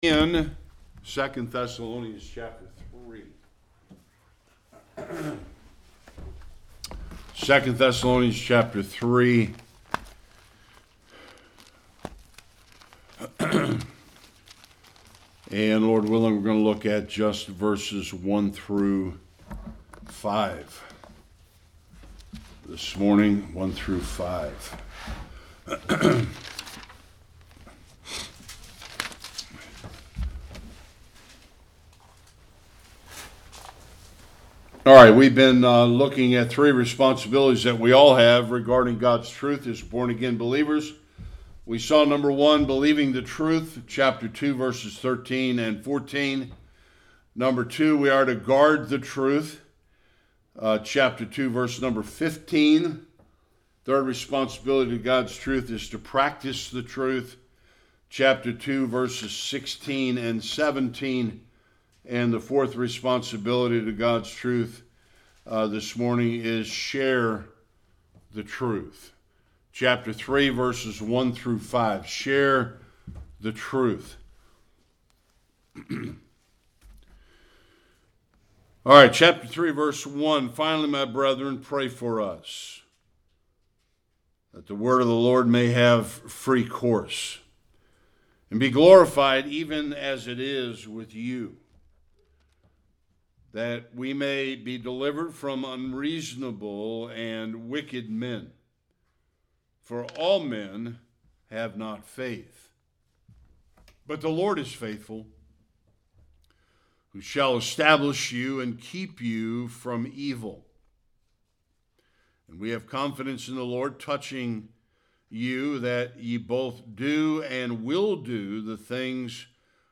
1-5 Service Type: Sunday Worship Christians are to Believe